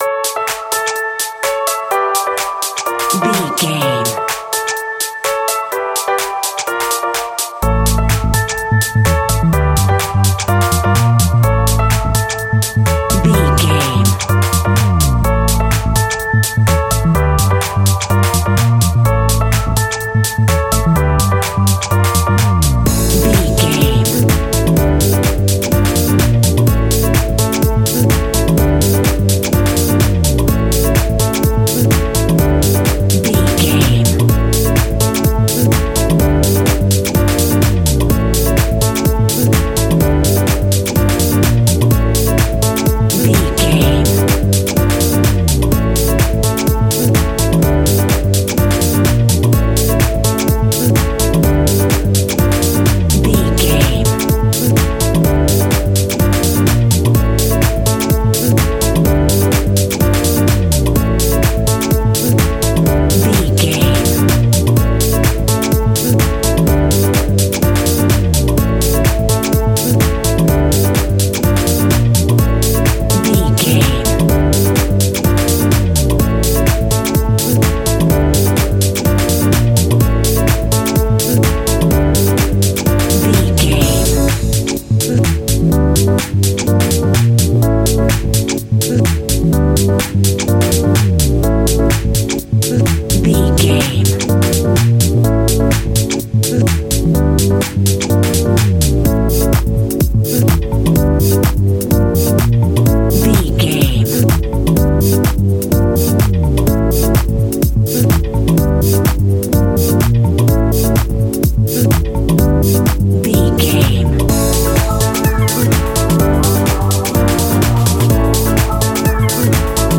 Mixolydian
groovy
uplifting
energetic
funky
electric piano
synthesiser
bass guitar
drum machine
funky house
upbeat
clavinet
horns